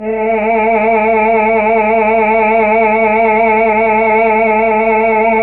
Index of /90_sSampleCDs/Roland LCDP09 Keys of the 60s and 70s 1/KEY_Chamberlin/VOX_Chambrln Vox